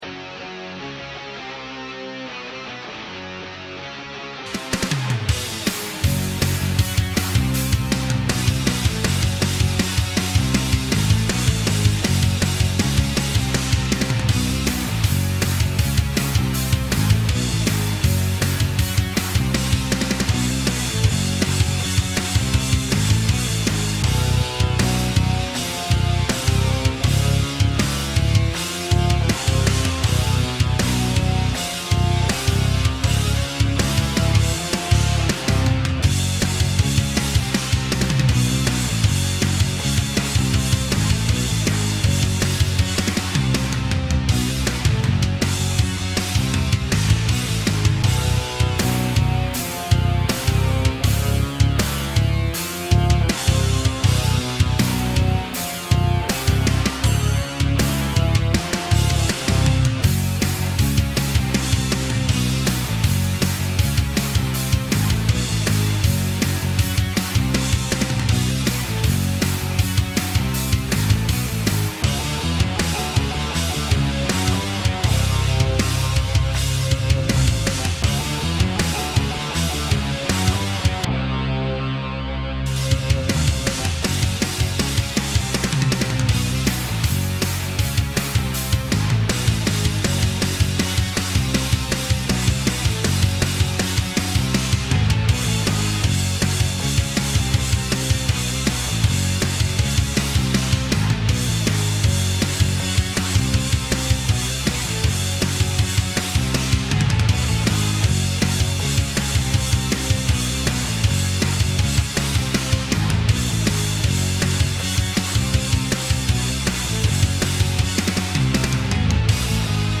guitar hero - rapide - rock - guitare electrique - harmonica